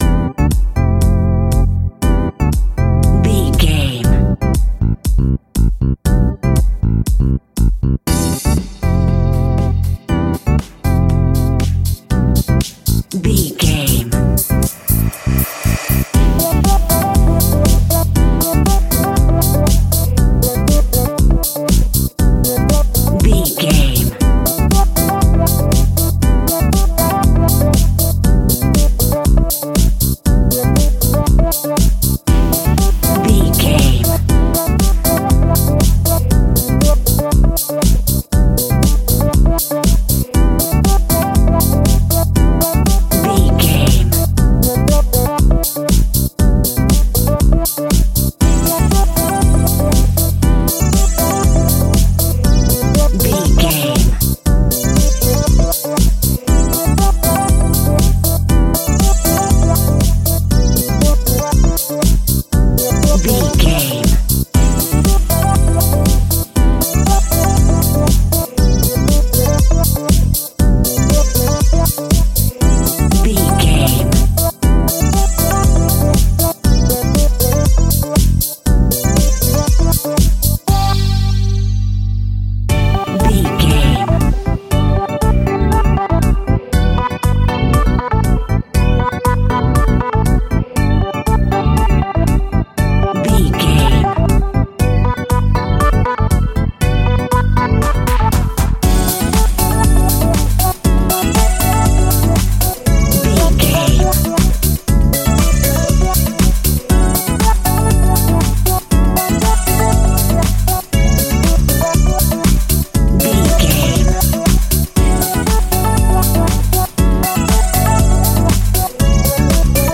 Funky House Groove Sounds.
Aeolian/Minor
groovy
uplifting
driving
energetic
repetitive
electric organ
bass guitar
drums
synthesiser
upbeat